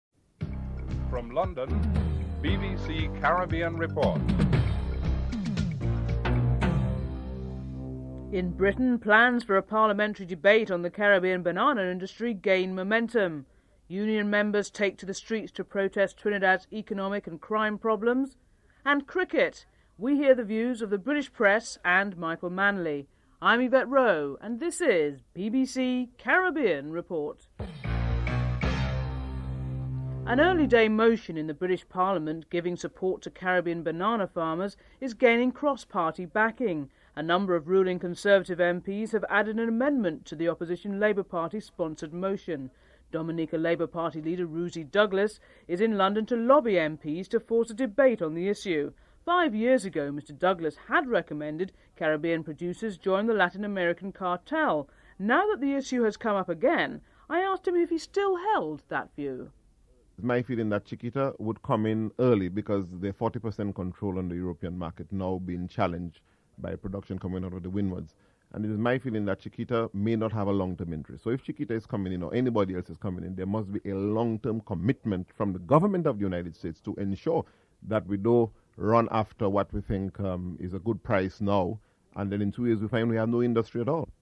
Theme music (14:14-15:00)